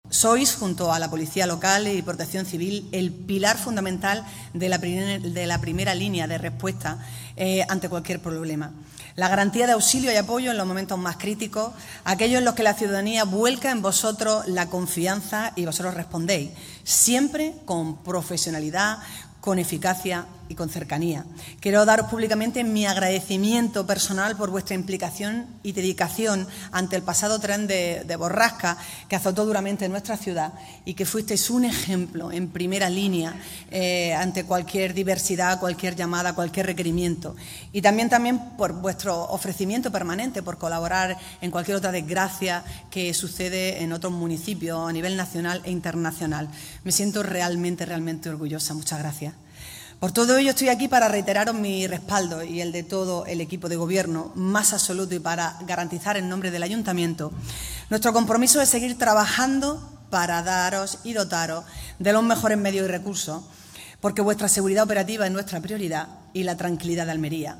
Así se lo ha manifestado este viernes en el acto institucional celebrado en el Parque de Bomberos de Almería con motivo de la celebración de su Patrón, San Juan de Dios.